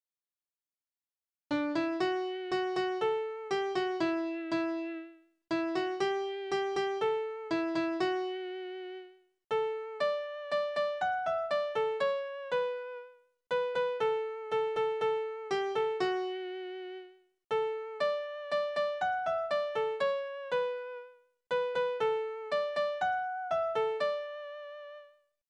Naturlieder
Tonart: D-Dur
Taktart: 4/4
Tonumfang: Undezime
Besetzung: vokal